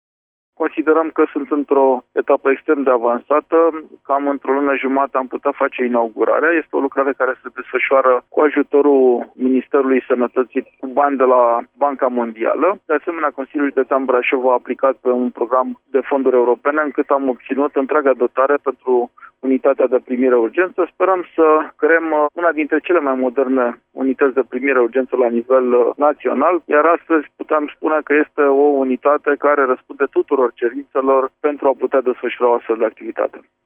Lucrările sunt aproape gata, iar investiţia se ridică la 3 milioane de euro, a anunţat preşedintele Consiliului Judeţean Braşov, Adrian Veştea: